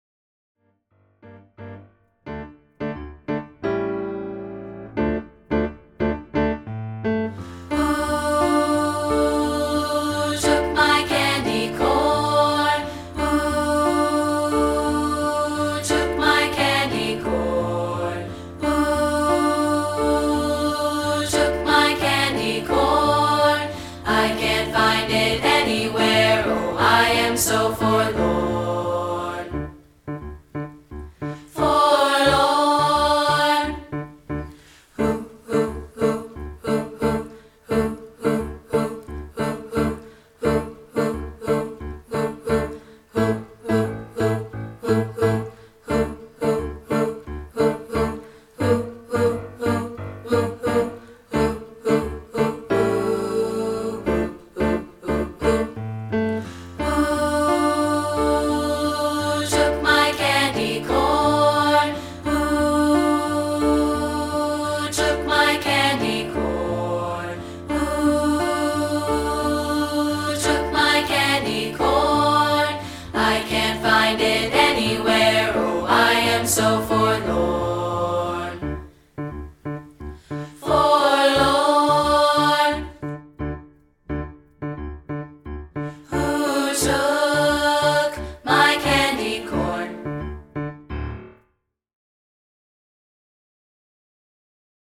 including a rehearsal track of part 3, isolated